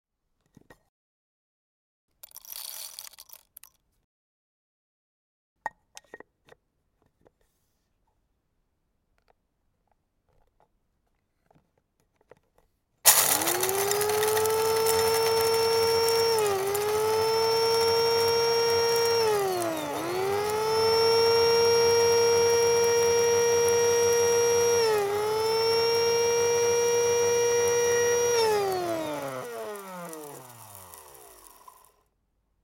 Philips Type HM3210 coffee grinder